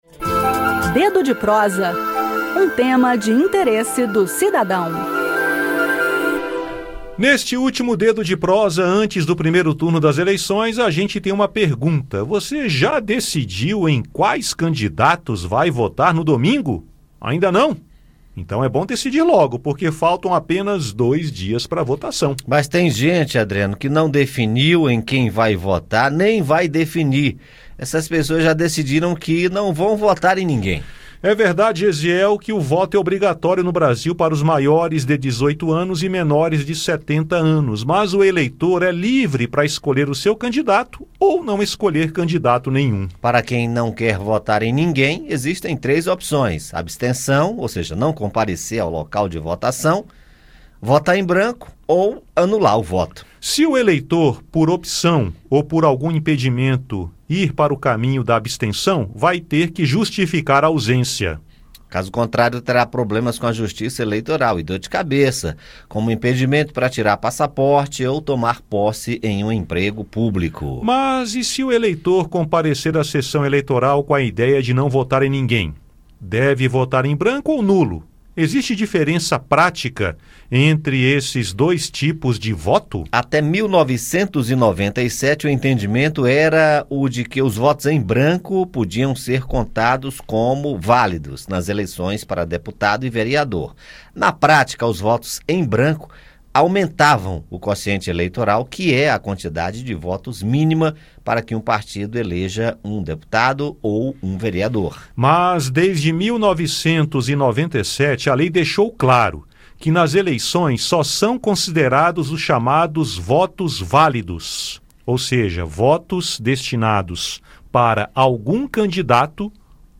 No bate-papo desta sexta-feira